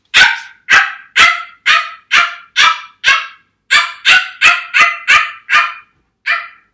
Animal Shih Tzu » Dog Shih Tzu Bark Single 03
描述：Shih Tzu dog, single bark
标签： Barking Animal Dog ShihTzu Bark
声道立体声